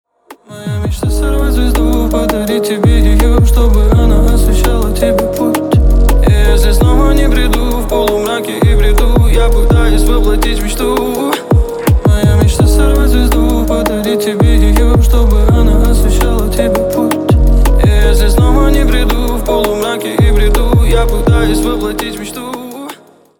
• Качество: 320, Stereo
лирика
романтичные